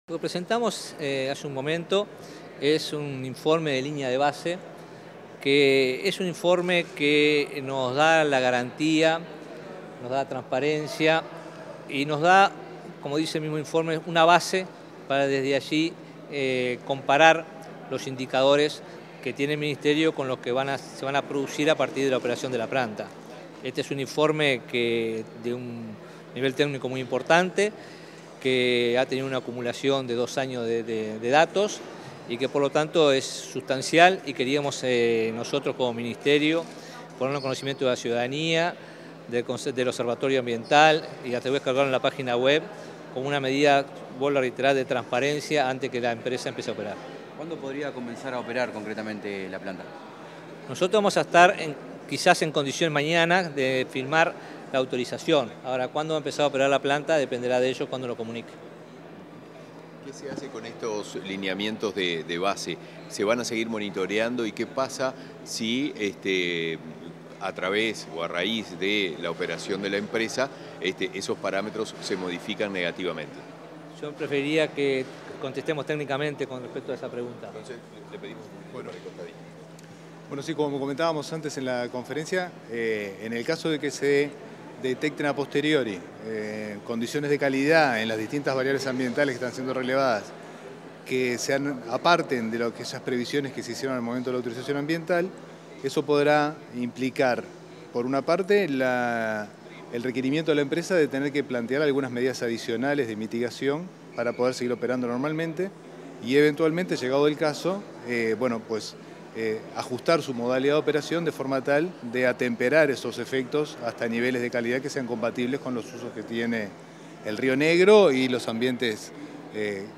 Declaraciones del ministro de Ambiente, Robert Bouvier
Declaraciones del ministro de Ambiente, Robert Bouvier 13/04/2023 Compartir Facebook X Copiar enlace WhatsApp LinkedIn Tras la presentación de la línea base que permitirá monitorear el impacto de la empresa UPM 2 dentro de los parámetros permitidos, este 13 de abril, el ministro de Ambiente, Robert Bouvier, realizó declaraciones a la prensa.